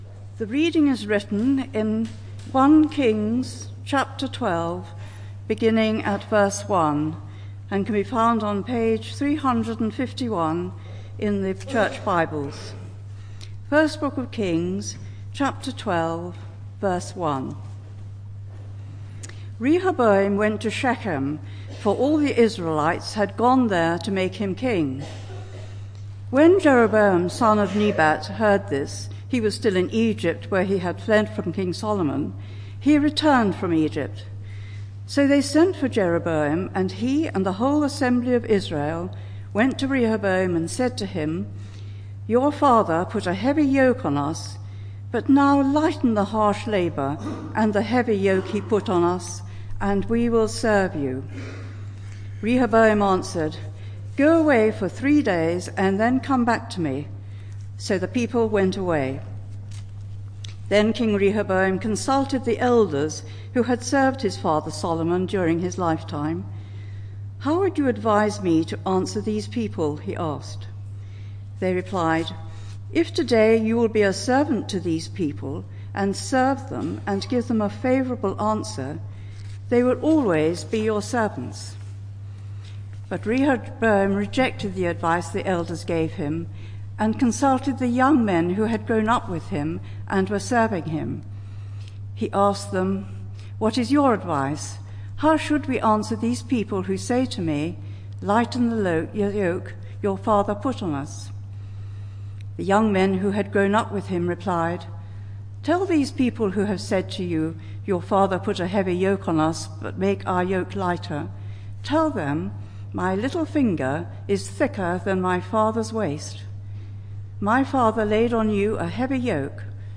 This sermon is part of a series: 27 October 2013